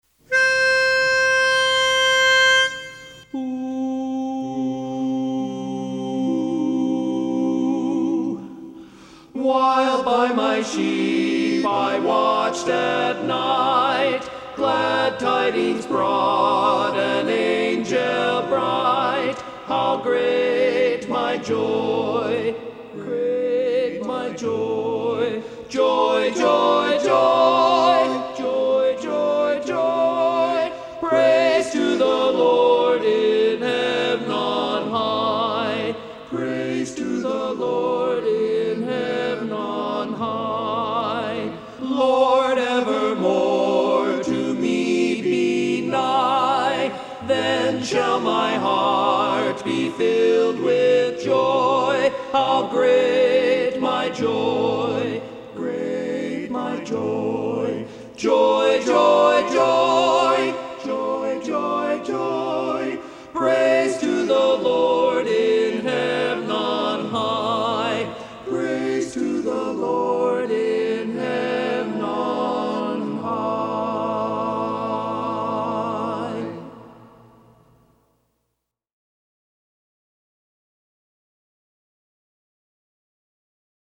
Christmas Songs
Barbershop
Lead